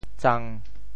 「糌」字用潮州話怎麼說？
糌 部首拼音 部首 米 总笔划 15 部外笔划 9 普通话 zān 潮州发音 潮州 zang1 文 中文解释 糌粑 [zanba,an Tibetan food,roasted qingke barley flour] 藏族的主食。